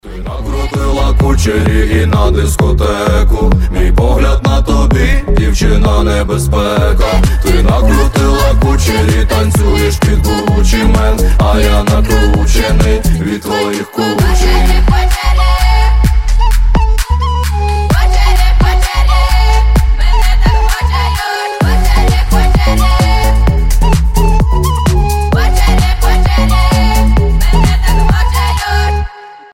Танцевальные рингтоны , Клубные рингтоны , Рэп рингтоны